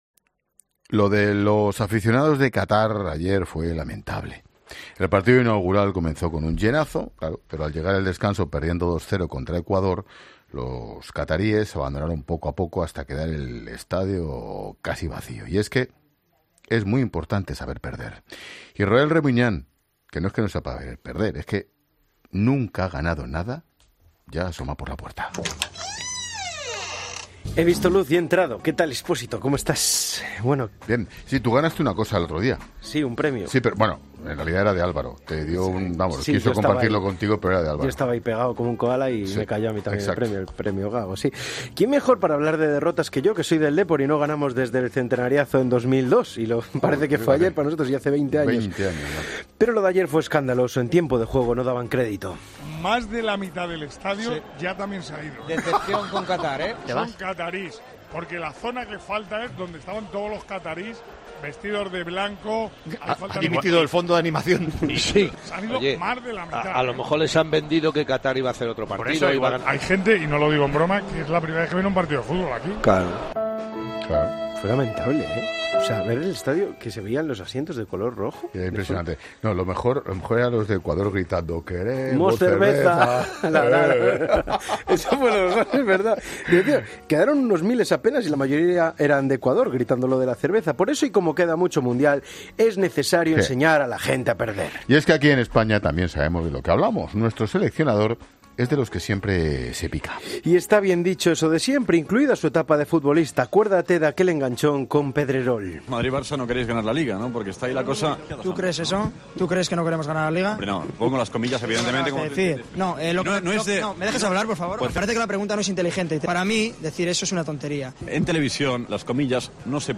La tensa entrevista entre Luis Enrique y Pedrerol que ha recordado Ángel Expósito: "Genio y figura"
Ángel Expósito no podía contener la risa y calificaba al entonces jugador del Barça como “genio y figura”.